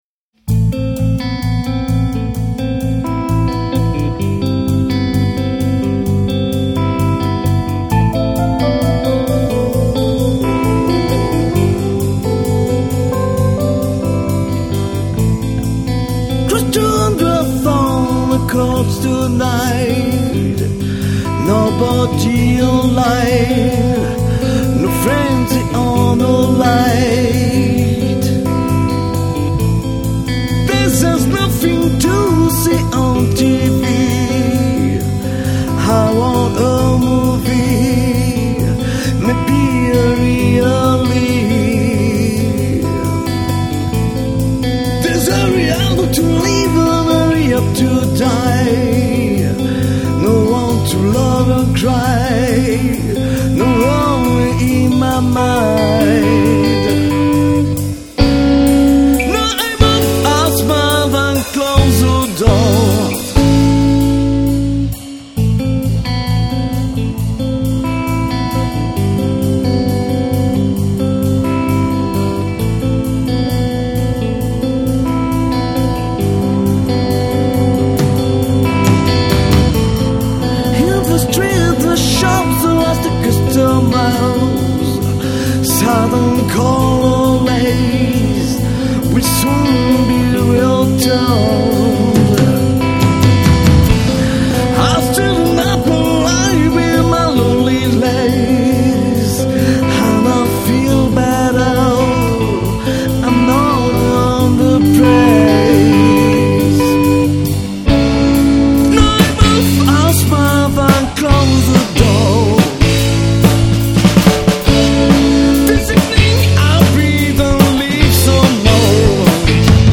Enregistrement studio
Guitare
Basse
Batterie
Chant / clavier